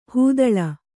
♪ hū daḷa